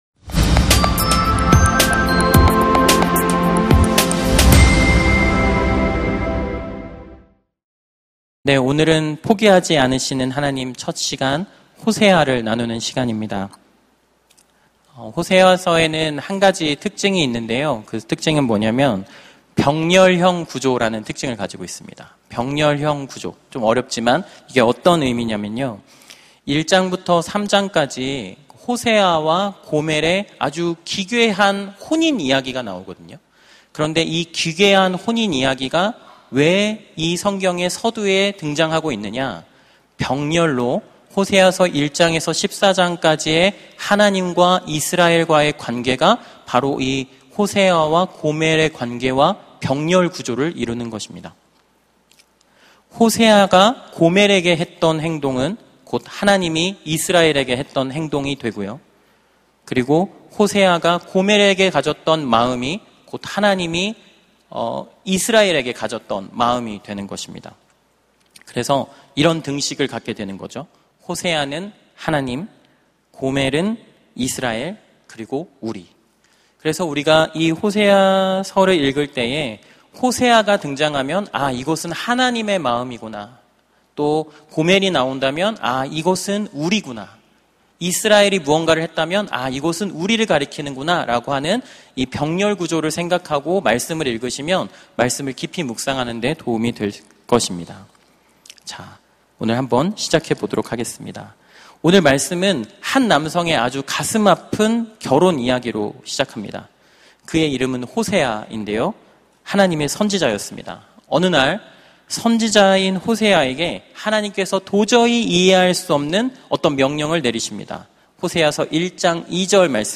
설교 : 파워웬즈데이 사랑하시는 하나님 설교본문 : 호세아 11:1-11